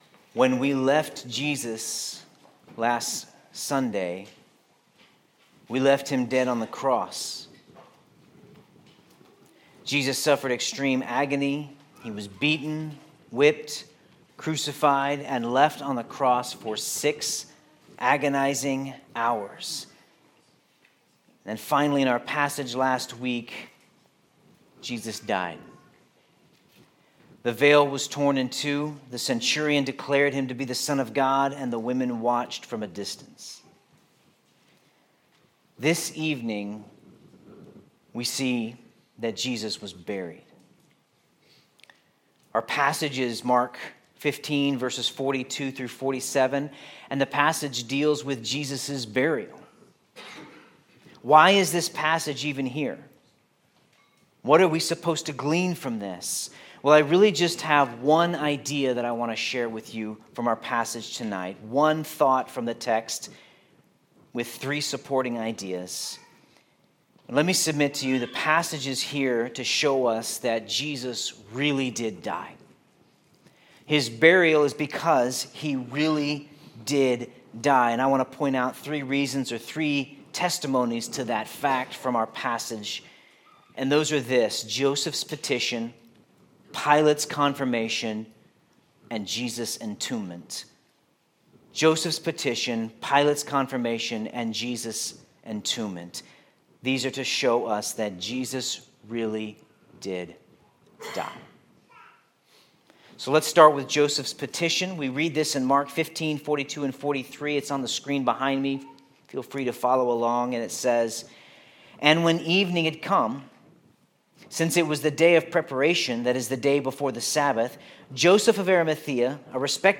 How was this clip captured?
On Good Friday, we remember Jesus, the God Who died.